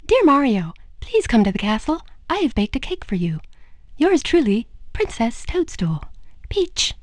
Peach's voice in Super Mario 64